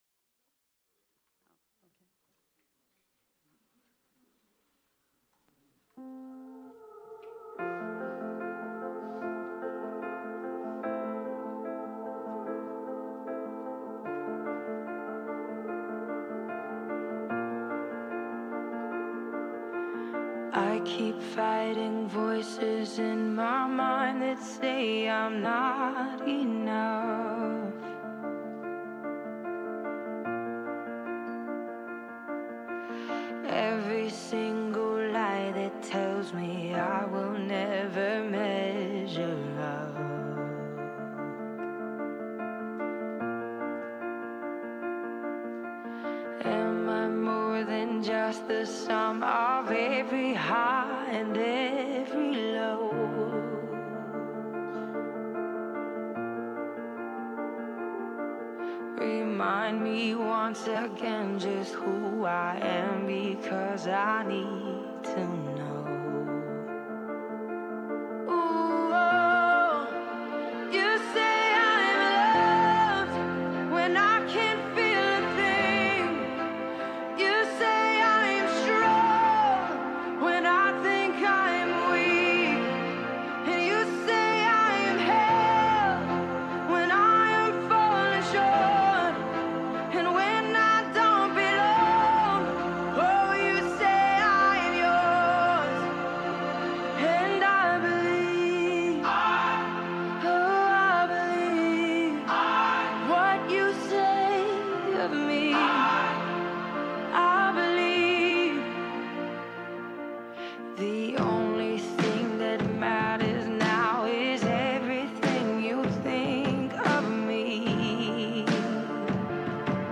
The audio recording below the video clip is an abbreviated version of the service. It includes the Message, Meditation, and Featured Song, and will be posted after editing.